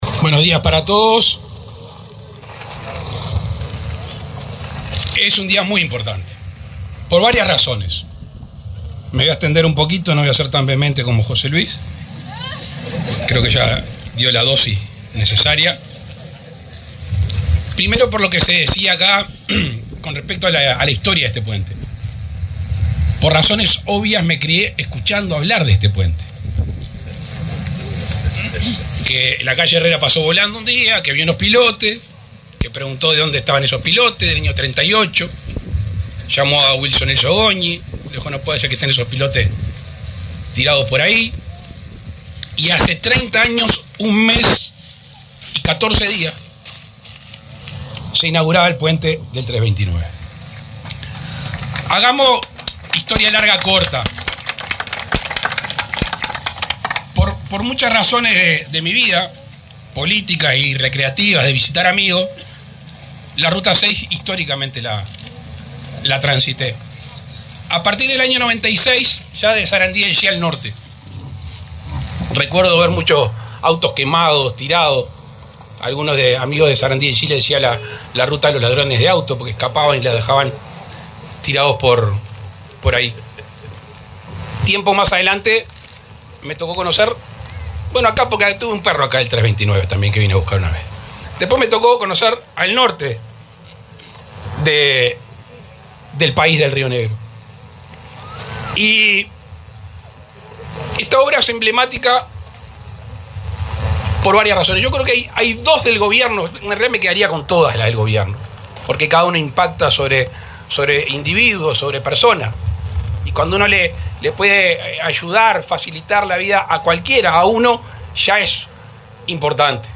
Palabras del presidente Luis Lacalle Pou
El acto se realizó en el kilómetro 329 de la mencionada vía nacional.